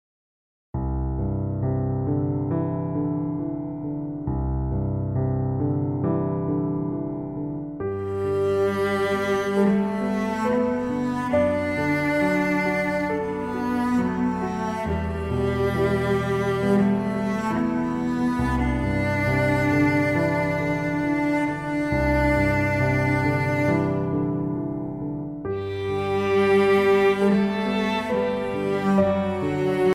Relaxing Violin and Heavenly Cello Music